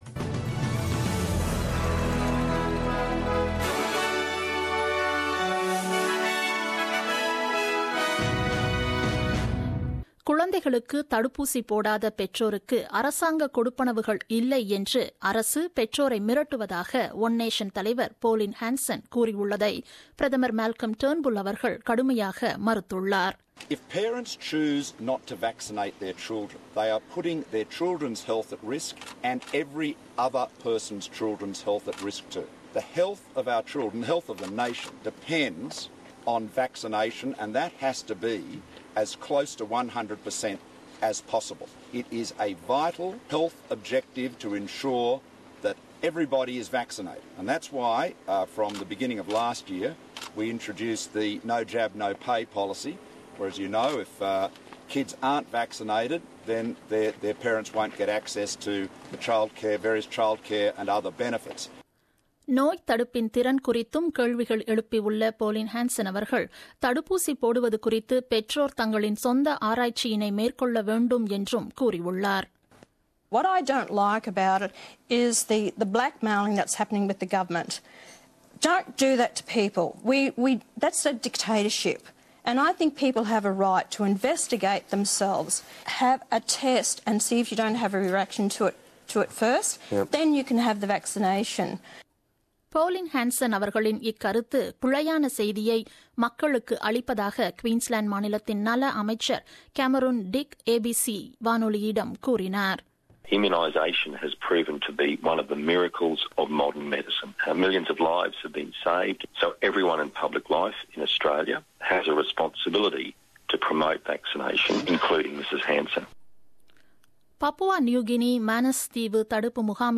The news bulletin broadcasted on 06 Mar 2017 at 8pm.